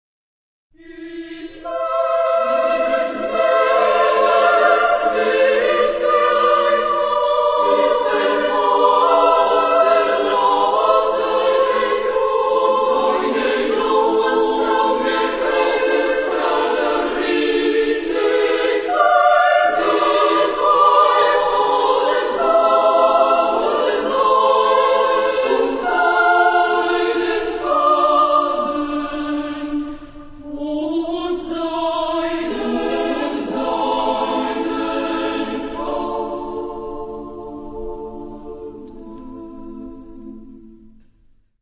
Genre-Style-Form: Lied ; Romantic ; Secular
Type of Choir: SSSAAA  (6 women voices )
Instruments: Piano (1)
Tonality: polytonal